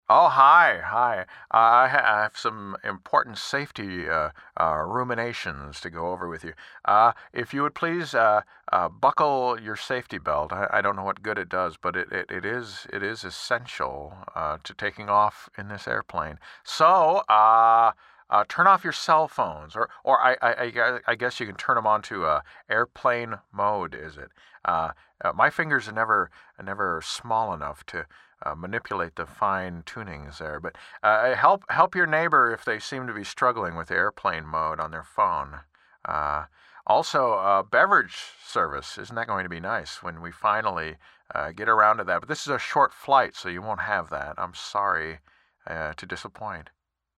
Jeff Goldblum – Airline Safety Announcements
Tags: celebrity announcements, celebrity impersonator, impressionist for hire, safety announcement voice